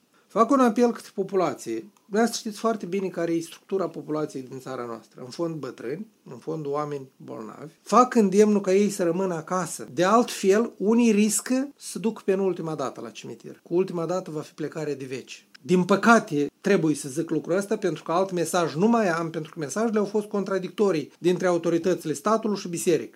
Șeful guvernului la Chișinău a atras atenția că mai sunt locuri în spitale doar pentru 5 zile și a distribuit mesajul transmis la Radio Moldova de directorul Agenției Naționale de Sănătate Publică, Nicolae Furtună.